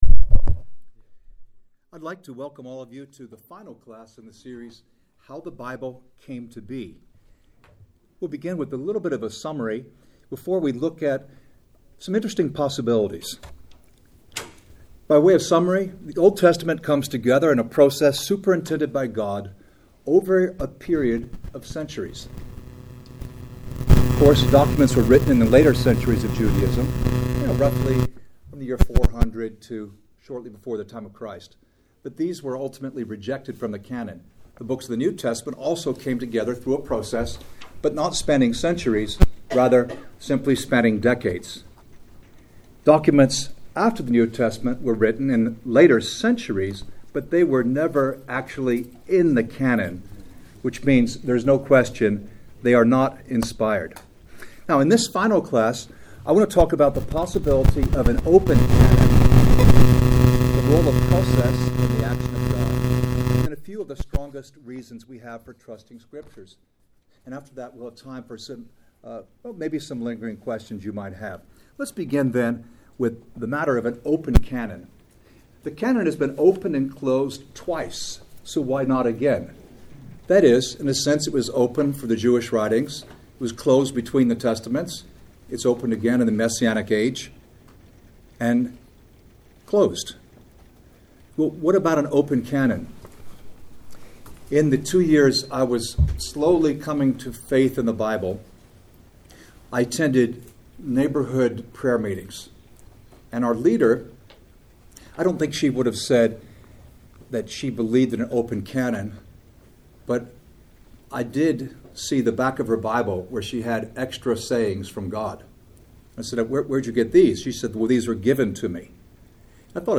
How-the-Bible-Came-to-Be-Class-5-Gospels-False-and-True.mp3